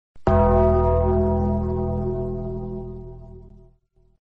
The Undertaker Bell - Botón de Efecto Sonoro